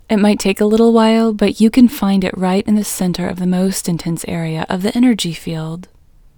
IN – the Second Way – English Female 8